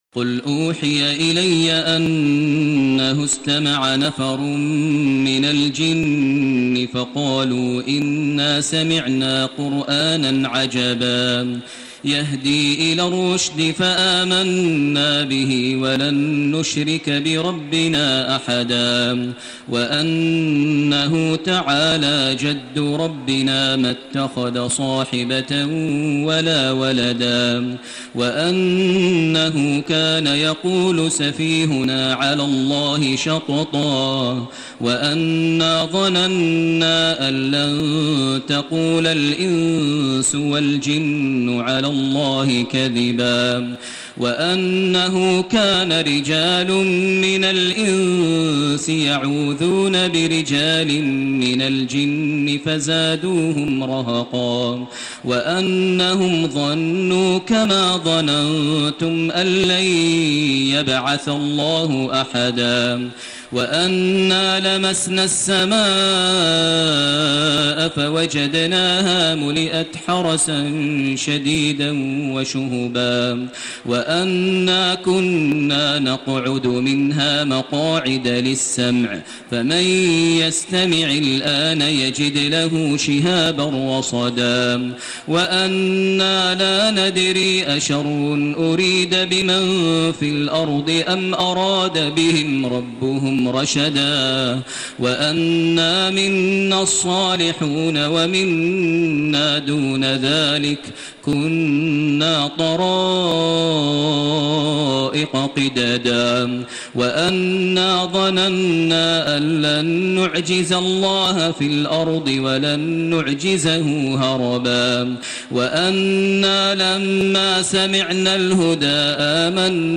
سورة الجن سورة المزمل سورة المدثر سوة القيامة سورة الانسان سورة المرسلات > تراويح ١٤٣٢ > التراويح - تلاوات ماهر المعيقلي